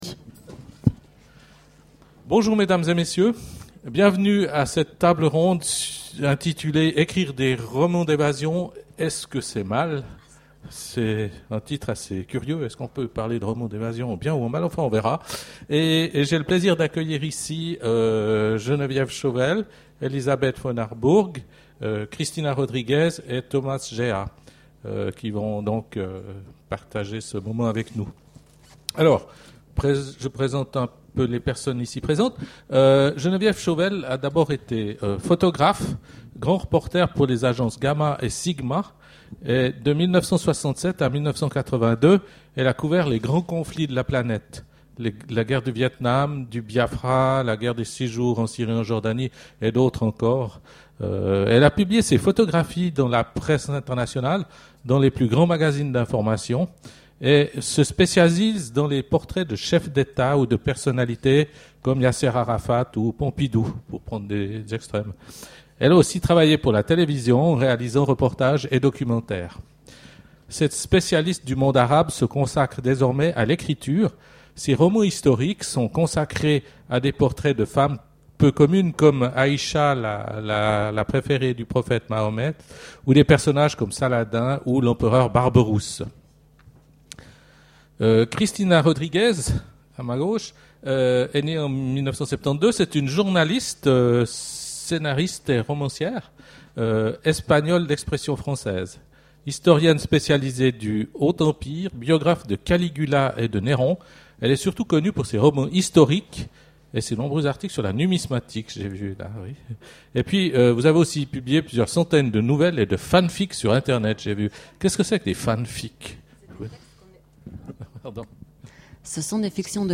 Imaginales 2011 : Conférence Ecrire des romans d'évasion, est-ce que c'est mal ?